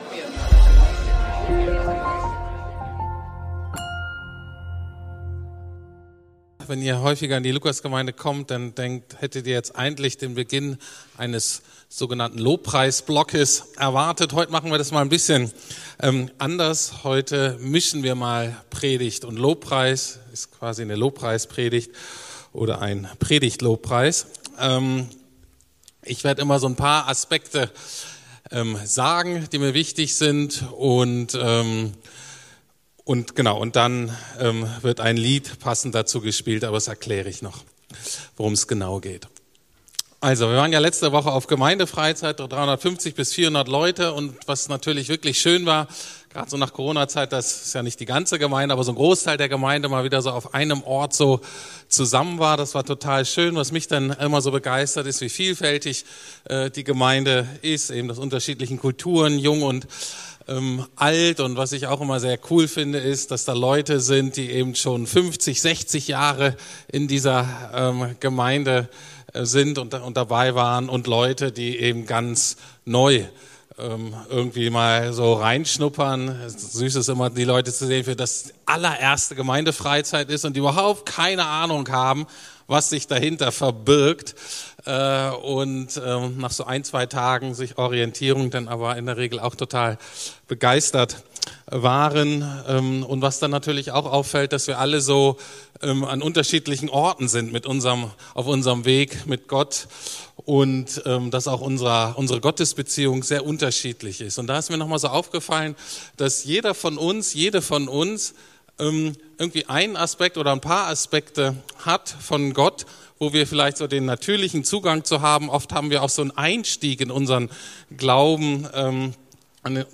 Warum sich jeder Mensch mehrmals bekehren muss ~ Predigten der LUKAS GEMEINDE Podcast